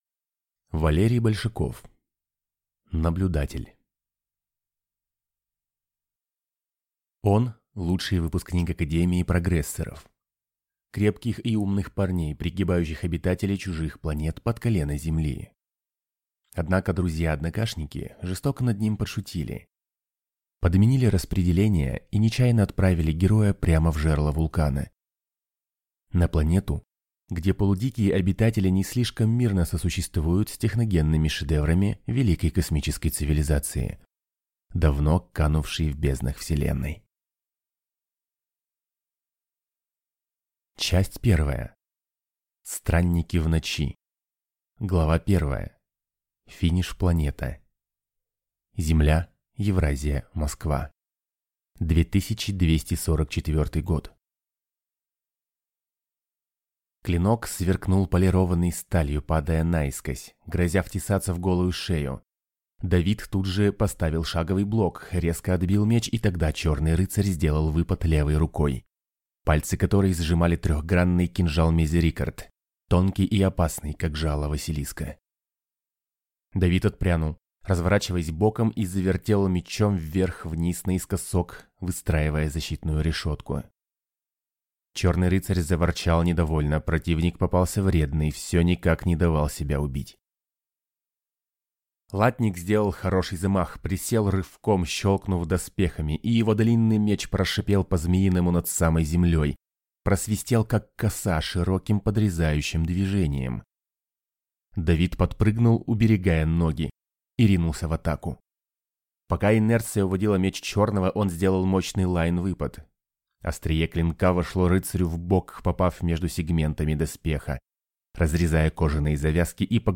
Аудиокнига Наблюдатель | Библиотека аудиокниг
Прослушать и бесплатно скачать фрагмент аудиокниги